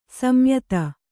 ♪ samyata